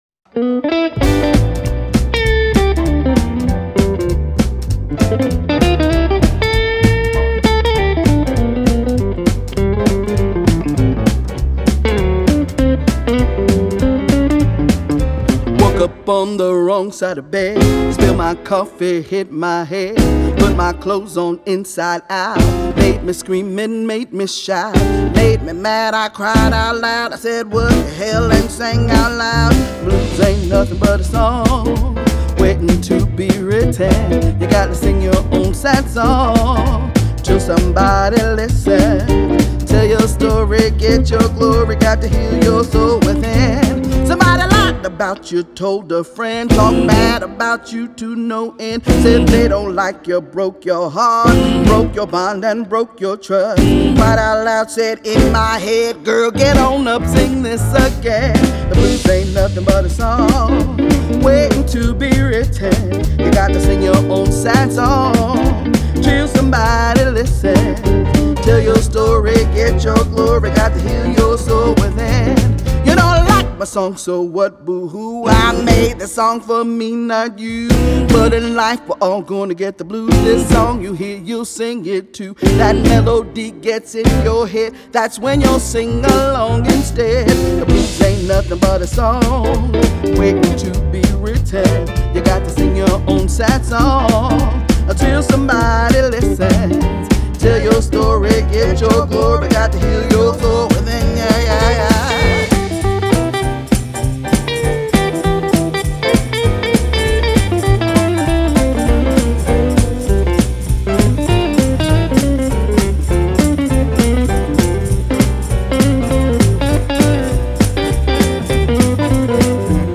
This catchy blues swing tune